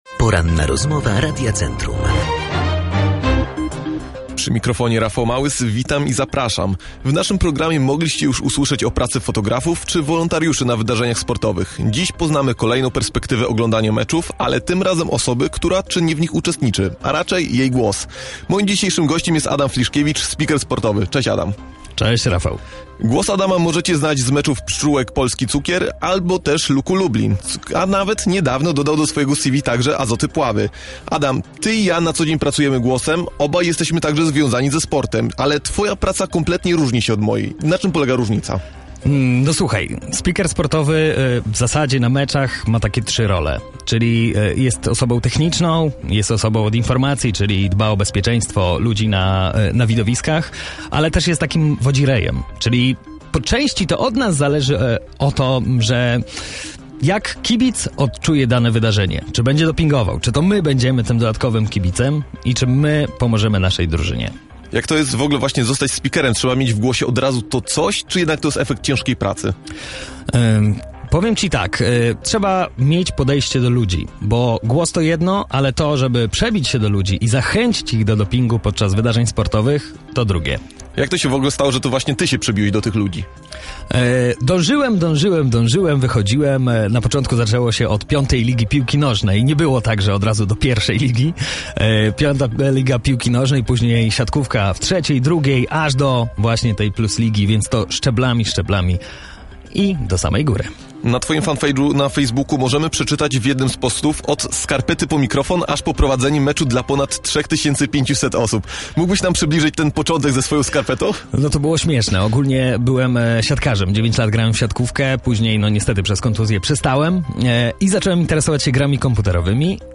Rozmowa-po-edycji-2.mp3